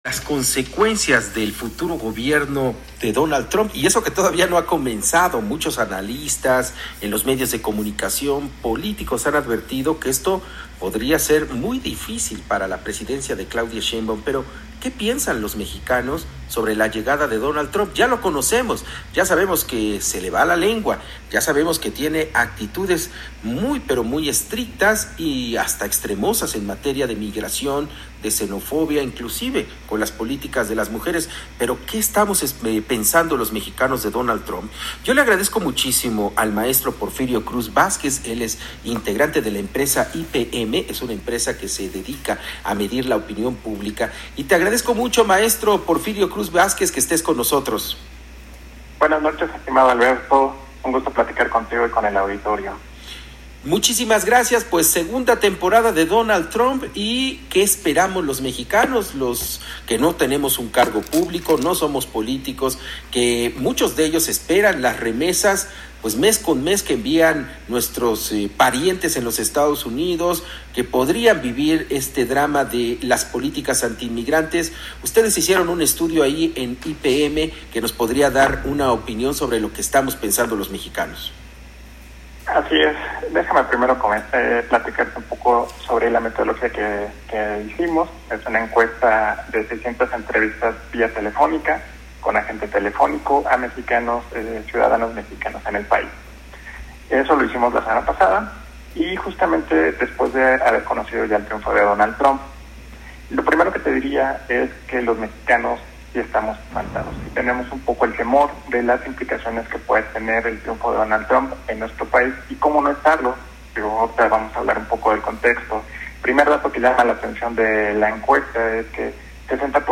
Consulta aquí abajo el reporte completo y escucha el audio de la entrevista de nuestro Director General